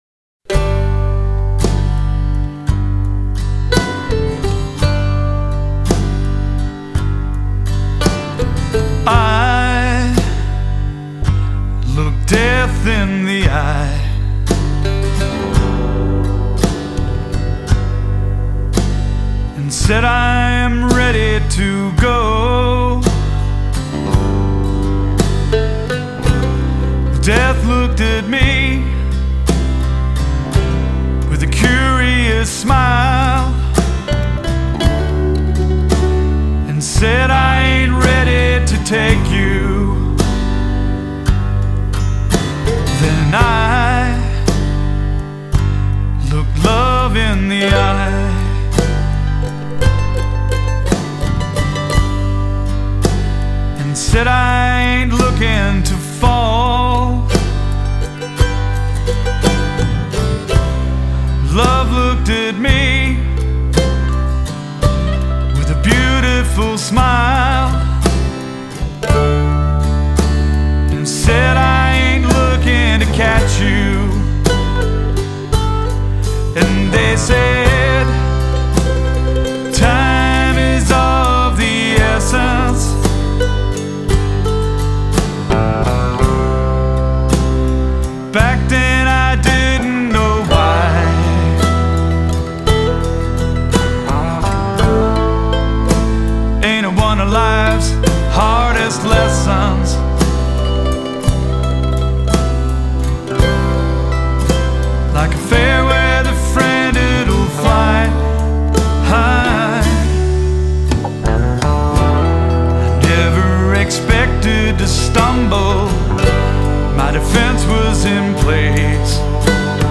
I am a songwriter and singer sharing stories.